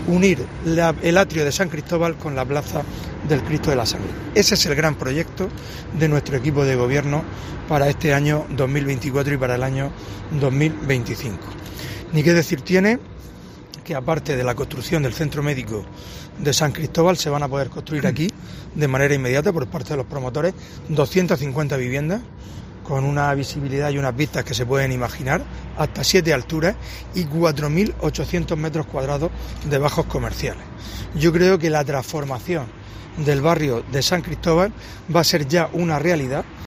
AUDIO: Fulgencio Gil, alcalde de Lorca